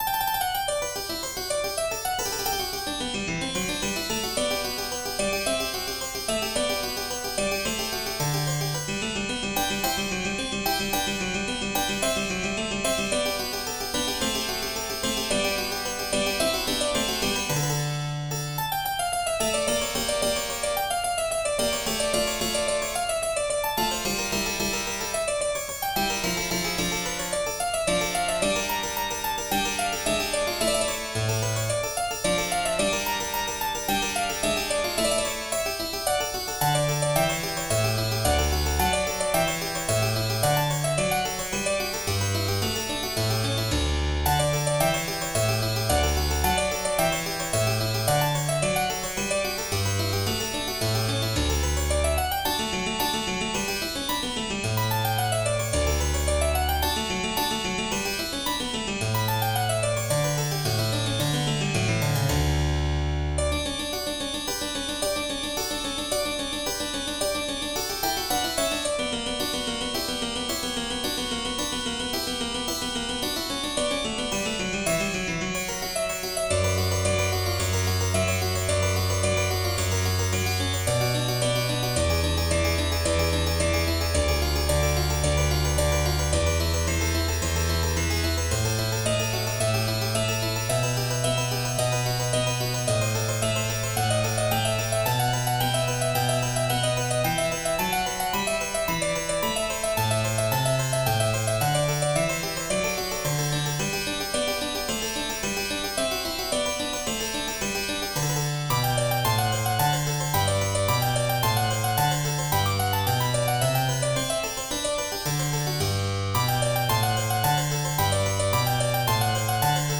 in G Major: Allegro